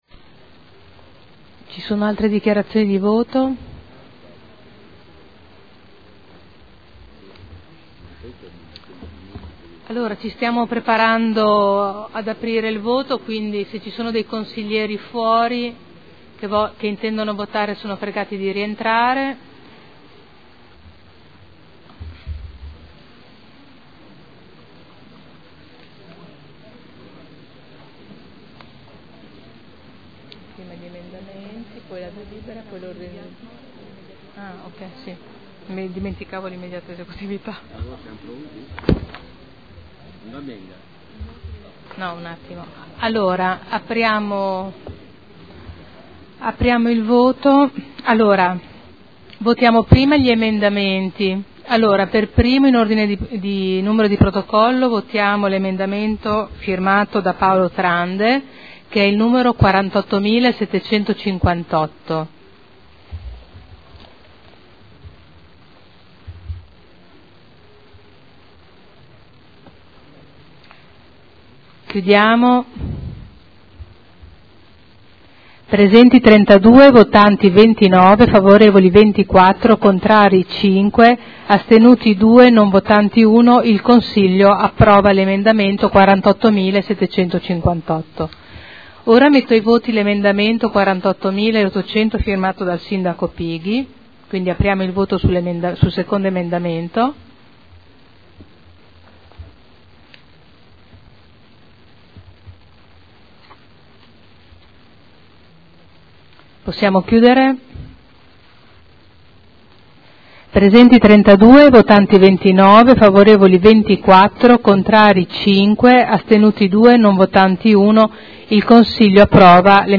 Presidente — Sito Audio Consiglio Comunale
Seduta del 03/05/2012. Mette ai voti proposta di deliberazione, emendamenti, immediata esecutività e Ordine del Giorno sulle scuole d'infanzia comunali.